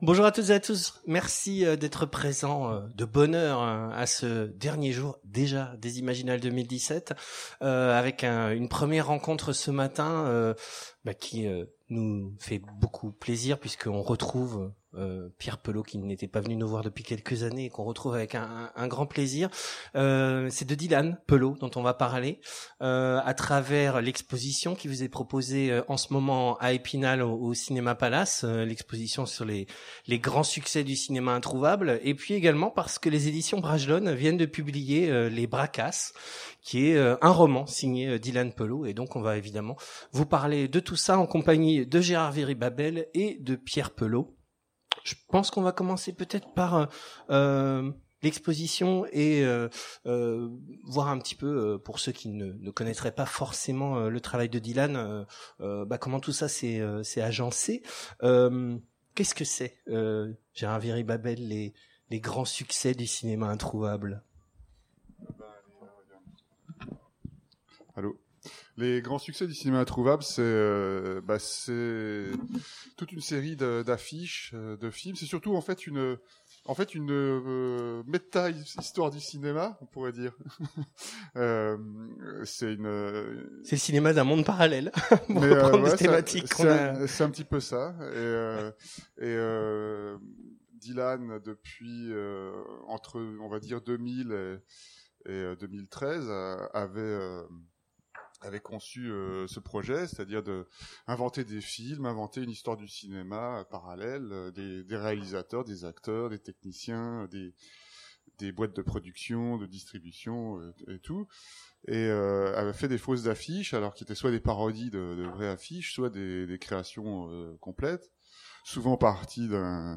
Imaginales 2017 : Conférence Les grands succès du cinéma introuvable…